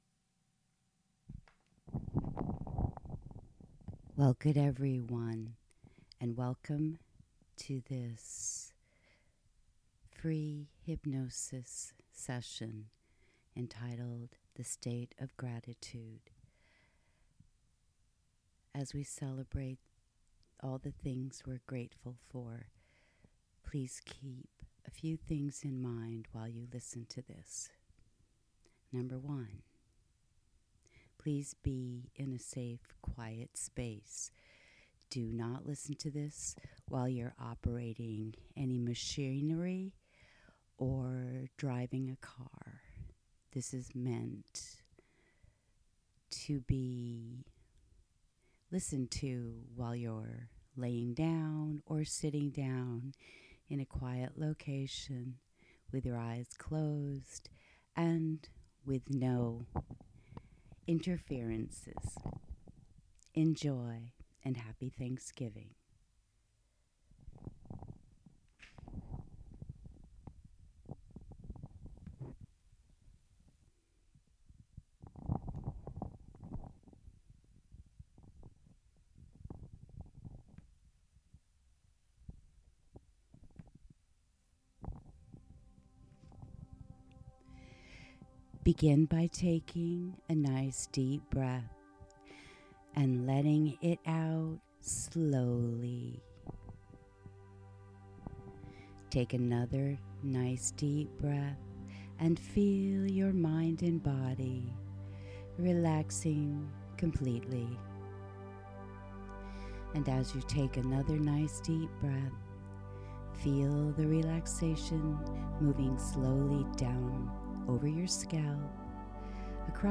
Gratitude Meditation – Teal Center